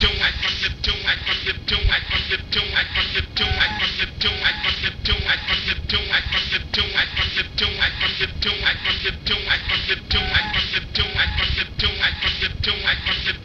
I Get Da Chewin Loop.wav